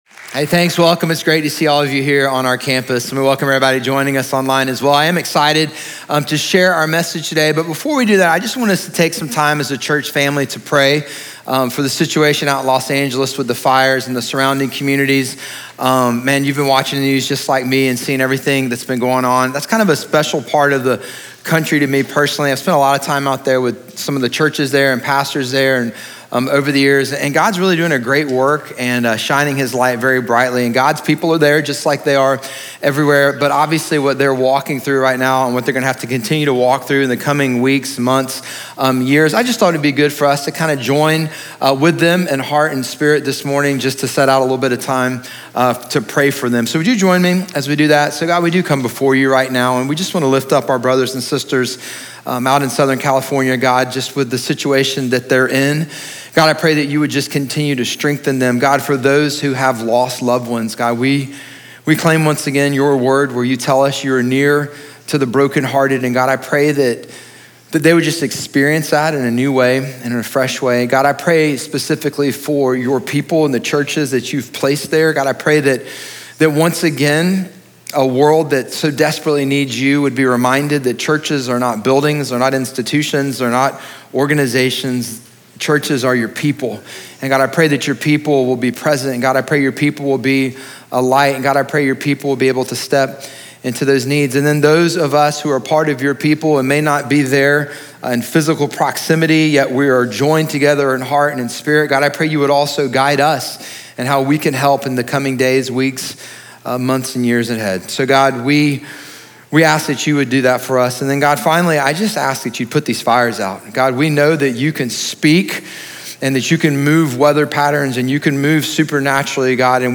Sermon Series Podcasts
Messages from NewHope Church in Durham, NC.